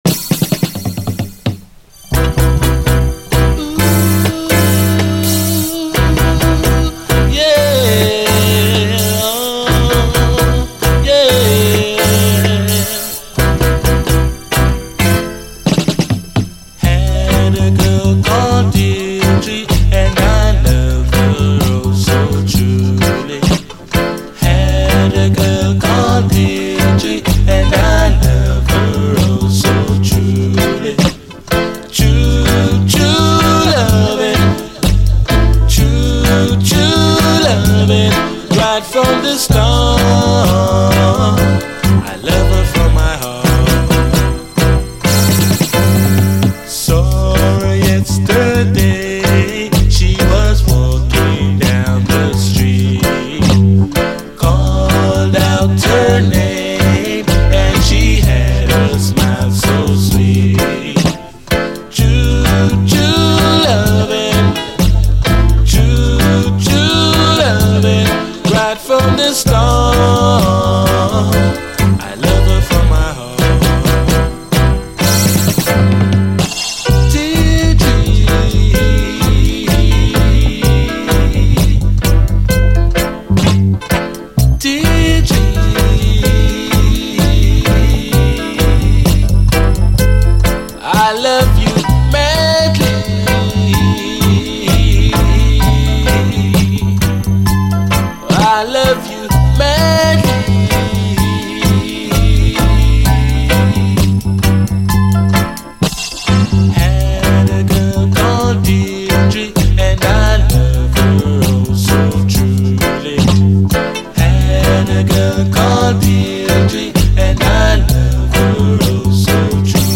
REGGAE
コーラスが美しくジンワリとあったかいイイ曲です。後半のダブまで曲が進むにつれてどんどん沁みてくる感じ。